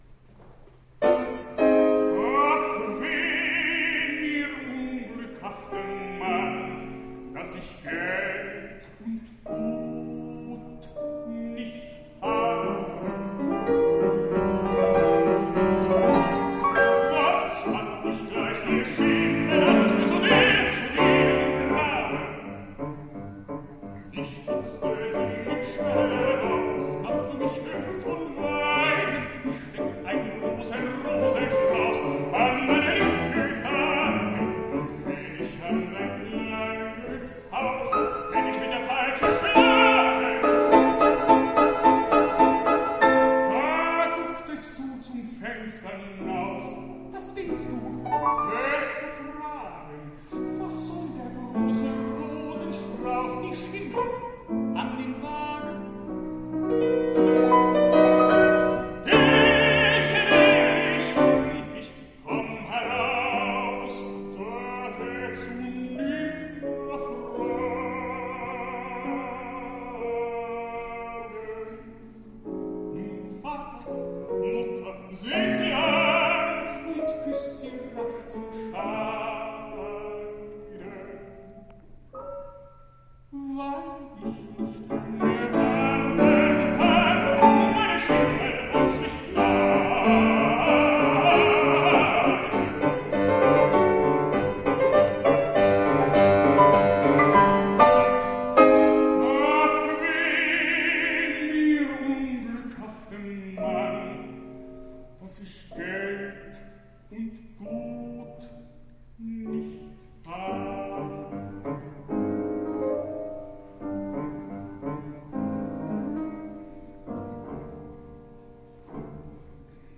Liederabend, München, Prinzregententheater, 22. Juli 2008
Sein baritonaler, hauchig-verschatteter Tenor erwies sich diesmal auch im Leisen sehr tragfähig.
Die „Schlichten Weisen“ servierte der Münchner mit leichter Hand, ohne die Ironie mancher Lieder (anhören: